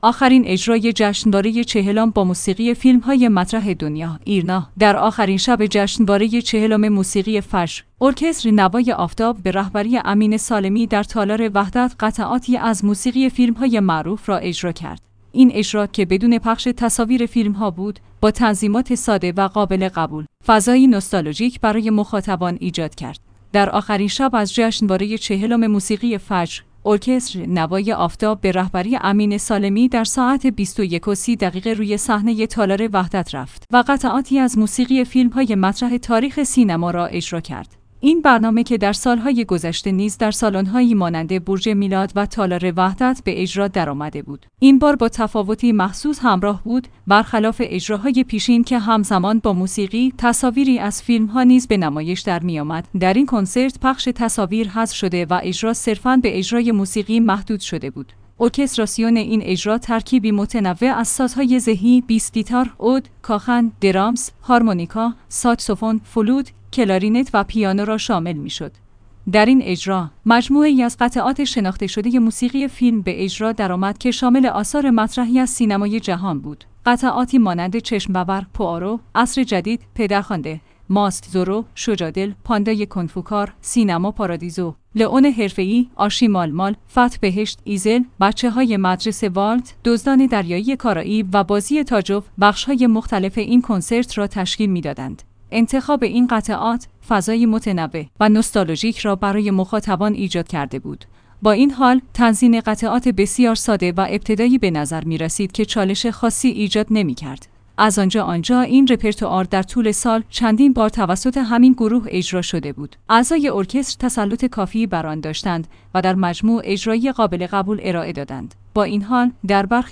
این اجرا که بدون پخش تصاویر فیلم‌ها بود، با تنظیمات ساده و قابل قبول، فضایی نوستالژیک برای مخاطبان ایجاد کرد.